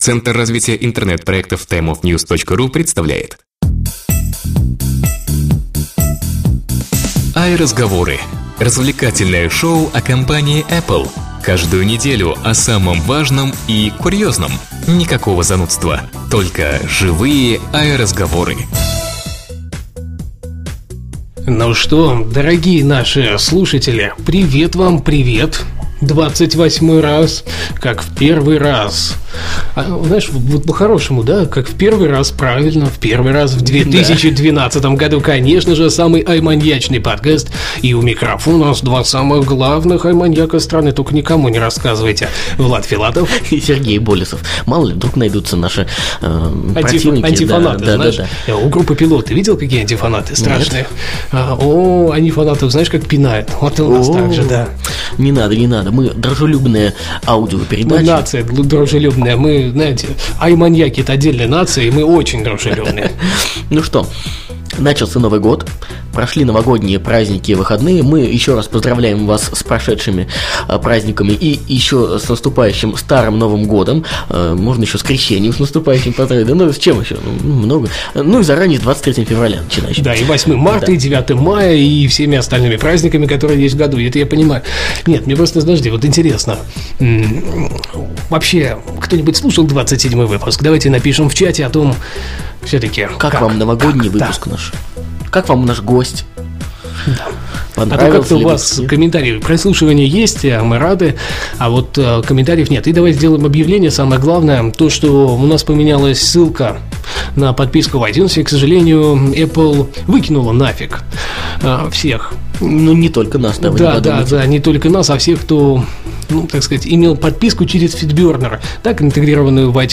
stereo Ведущие аудиошоу в свободной и непринужденной манере расскажут вам обо всех самых заметных событиях вокруг компании Apple за прошедшую неделю. Никакой начитки новостей, занудства, только живые "АйРазговоры".